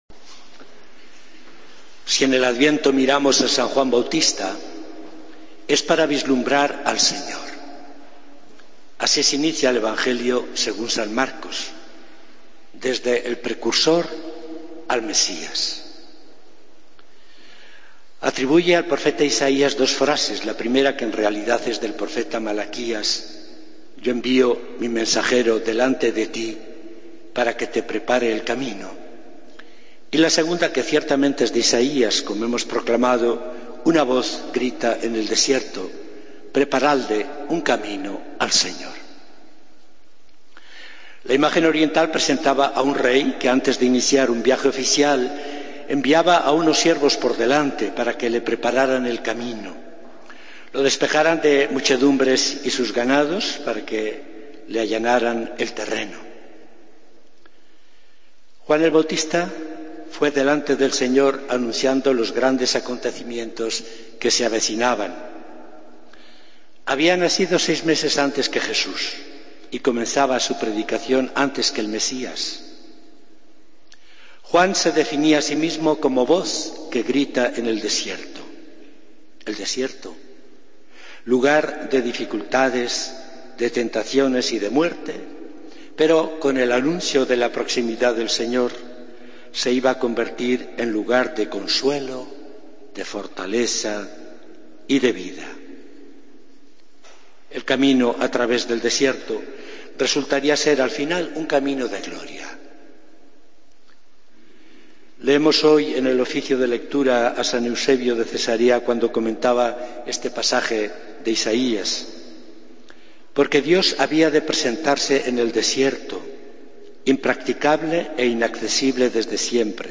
Homilía del Domingo 7 de Diciembre de 2014